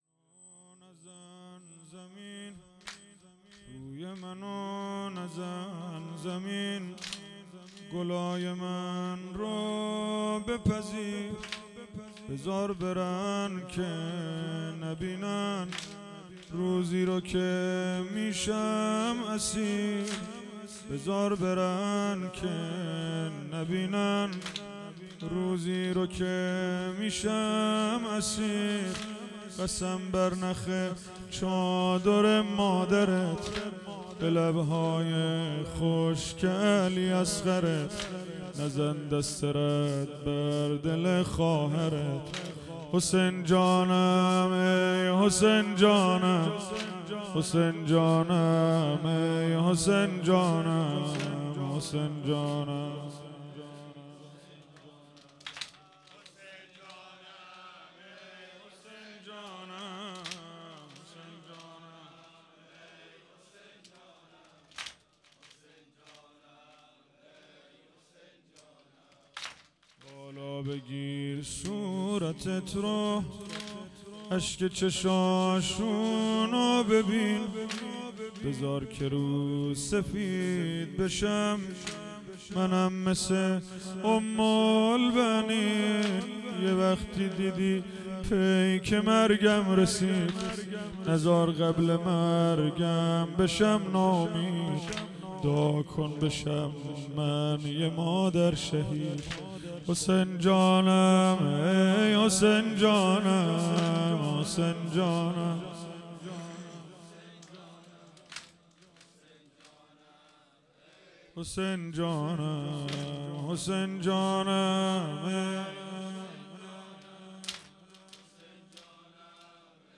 هیئت حسن جان(ع) اهواز - واحد
دهه اول محرم الحرام ۱۴۴۴